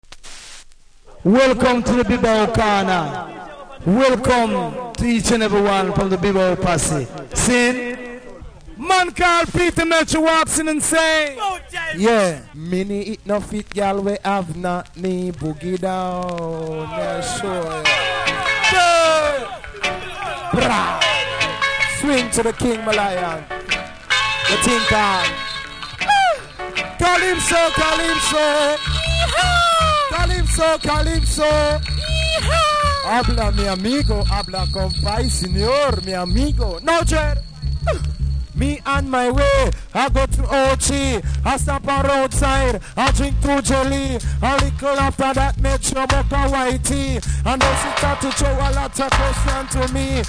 当時の貴重なライブ音源♪　往年の定番リズムでラバダブ！
キズもノイズもそこそこありますが聴けます。
あまり高音域が入ってないので録音音源は高音上げて録音してあります。